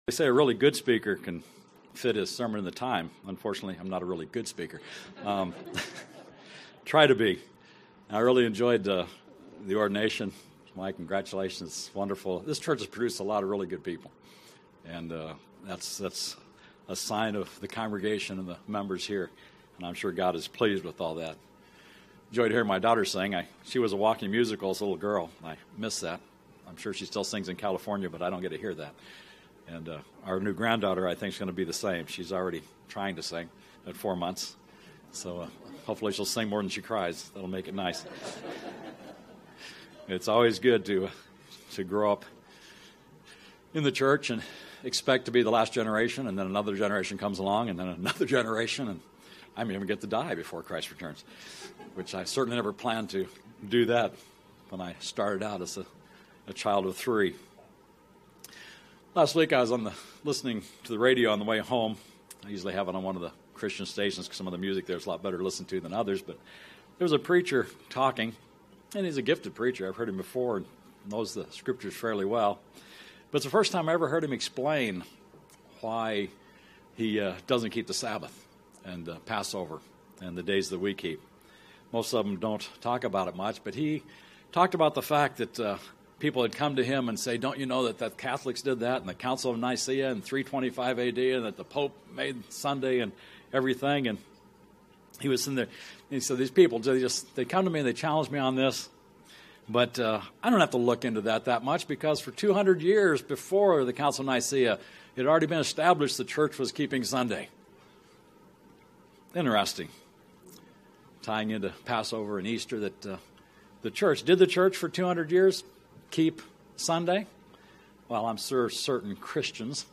In this sermon we look at 5 times in the Old Testament when the Israelites rededicated themselves to the Passover. We should use this as an opportunity to rededicate ourselves to God.